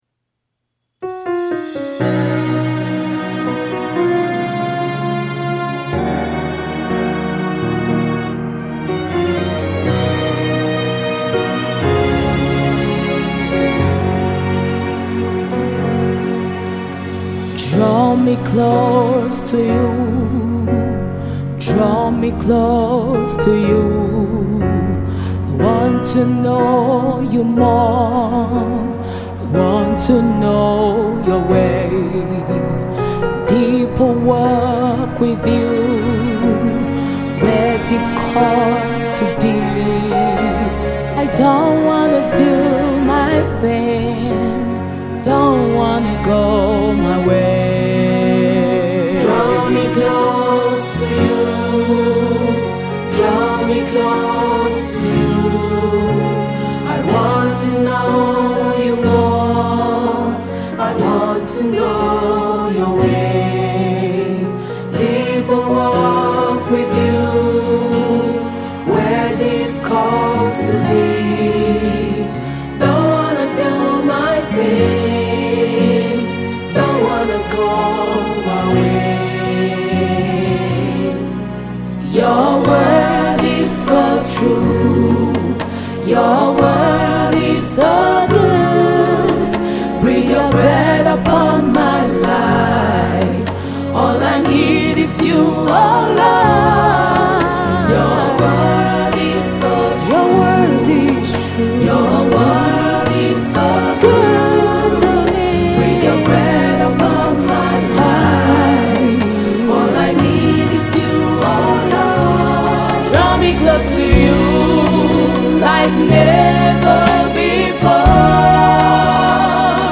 It is a song of prayer and intimacy worship with God.